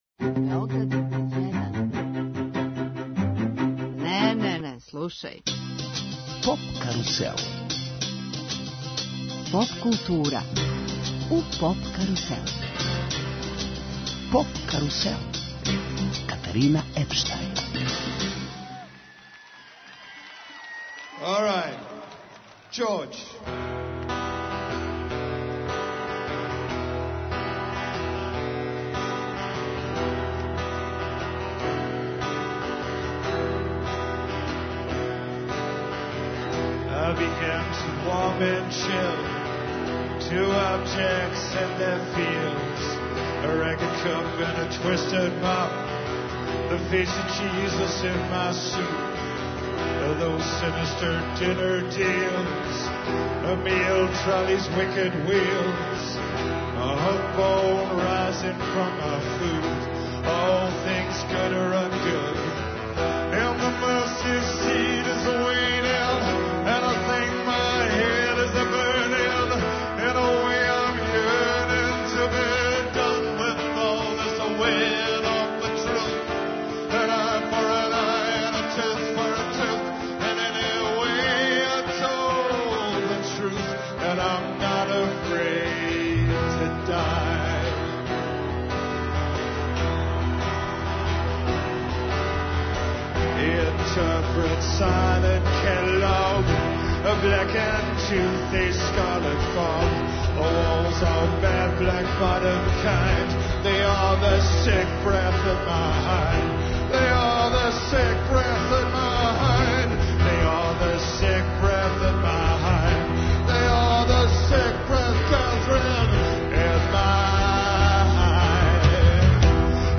Радио Београд 1 на Егзит фестивалу! Уживо са Петроварадинске тврђаве!